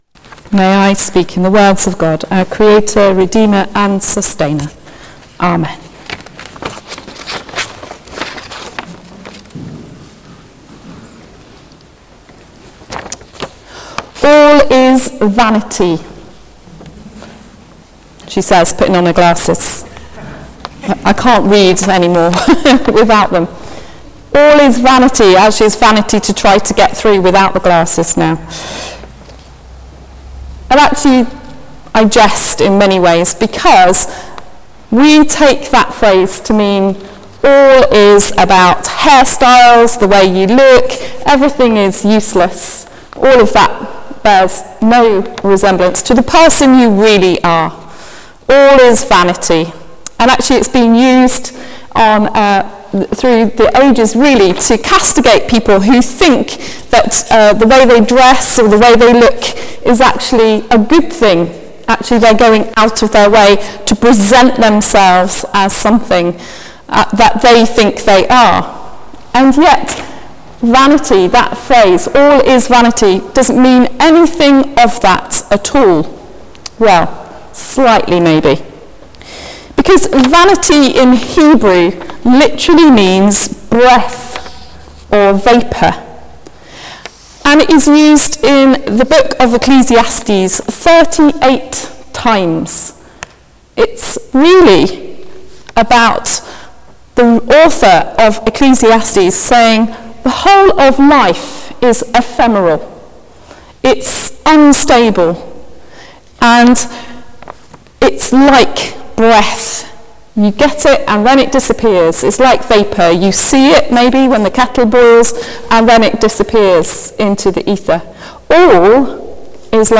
Sermon: All is Vanity | St Paul + St Stephen Gloucester